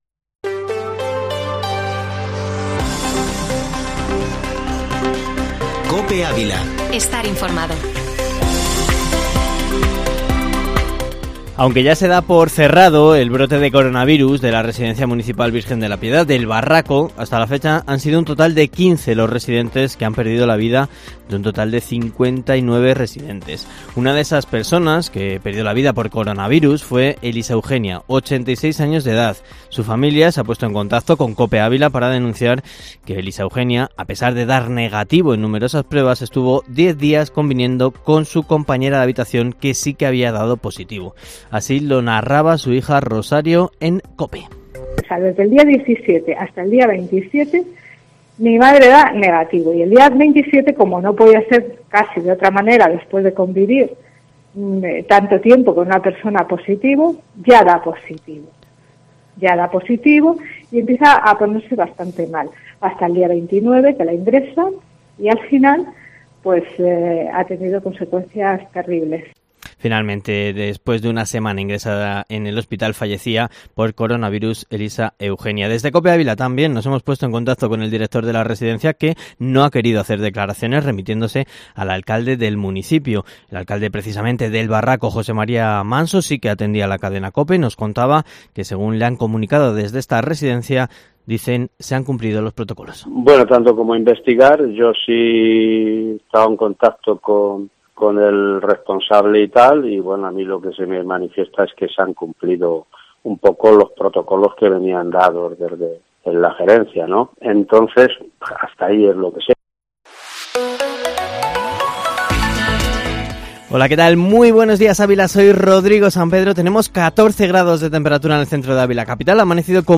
Informativo Matinal Herrera en COPE Ávila -23-sept